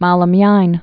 (moulə-myīn, -lä-myīn)